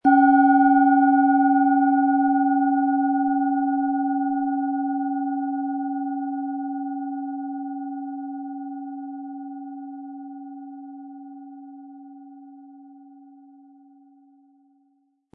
Tibetische Bauch-Herz-Kopf- und Fuss-Klangschale, Ø 10,5 cm, 100-180 Gramm, mit Klöppel
Im Preis enthalten ist ein passender Klöppel, der die Töne der Schale schön zum Schwingen bringt.